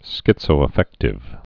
(skĭtsō-ə-fĕktĭv)